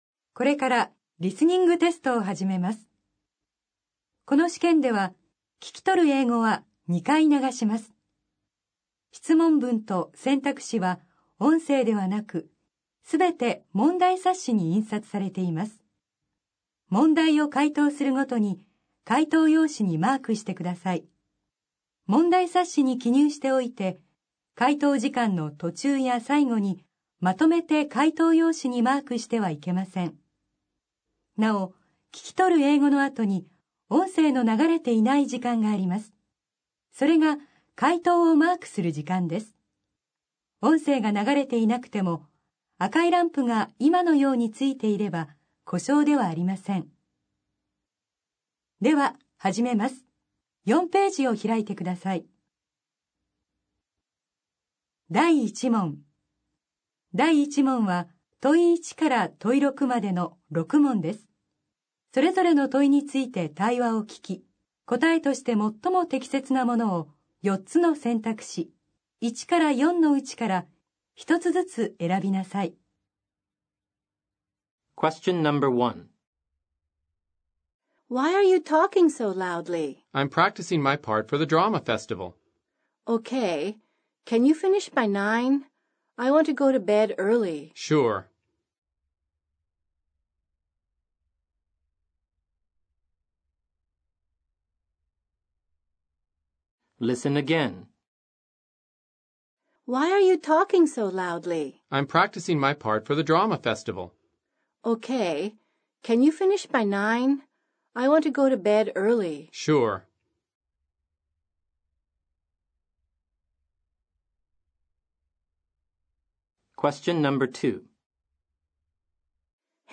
英語リスニング 過去問の全て